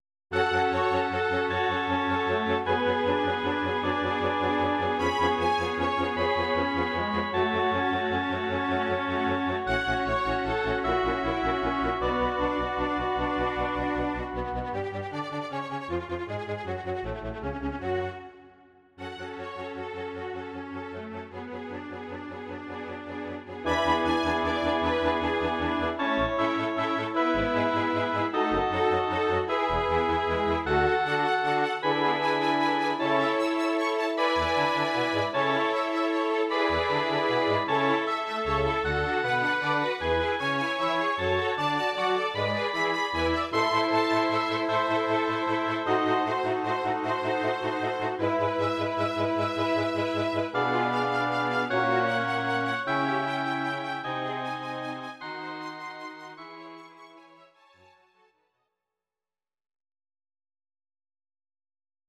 These are MP3 versions of our MIDI file catalogue.
Your-Mix: Instrumental (2074)